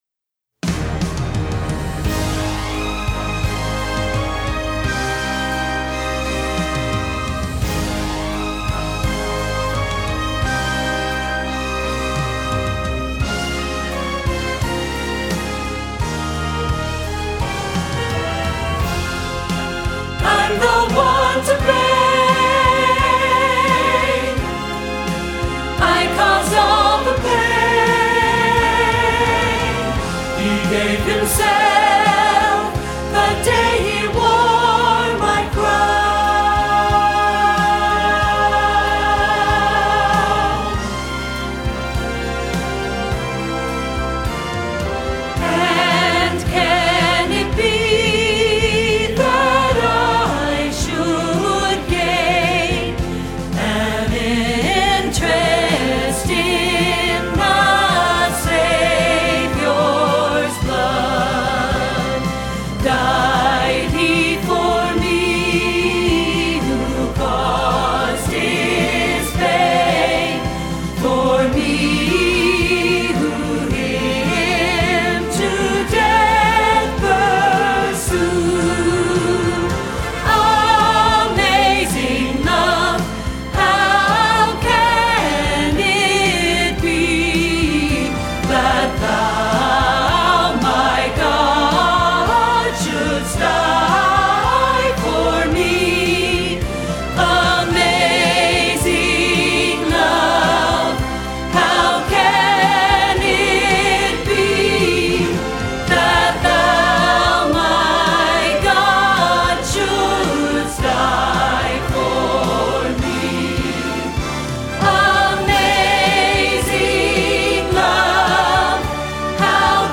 01+Crown+Him+King!+Opener+-+Alto.mp3